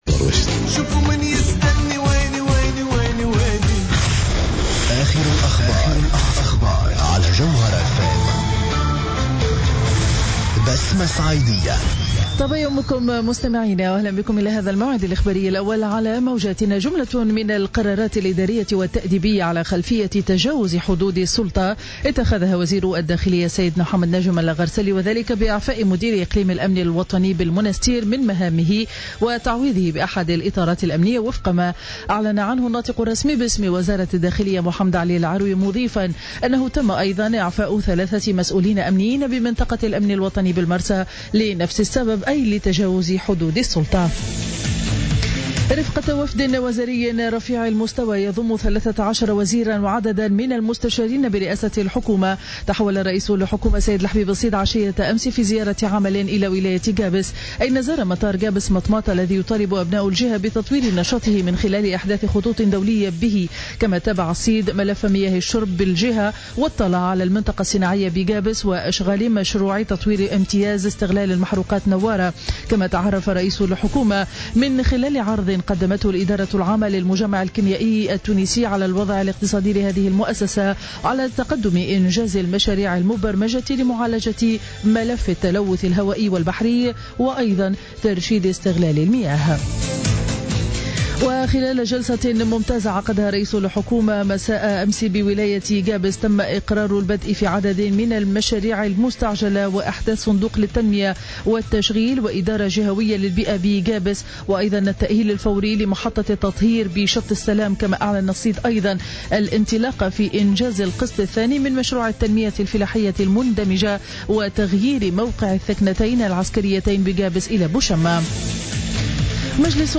نشرة أخبار السابعة صباحا ليوم الخميس 25 جوان 2015